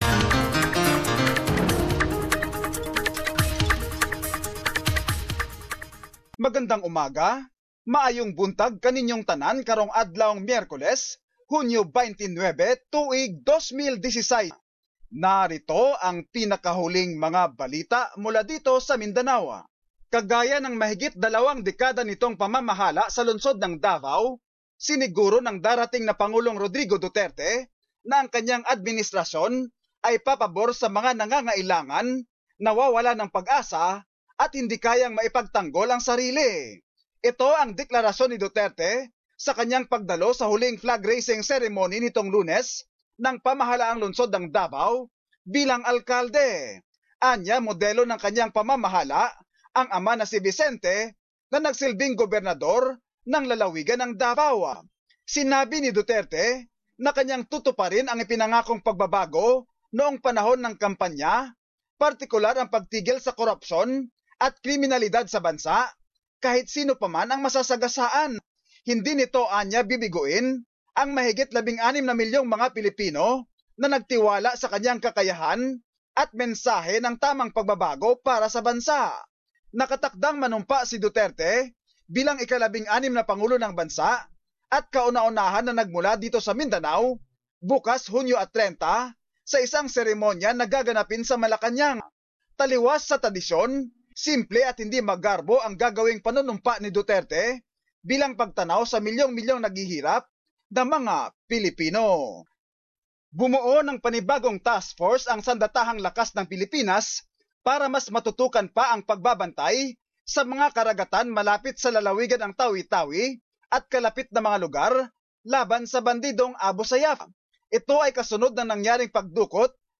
Mindanao News. Summary of latest news from the region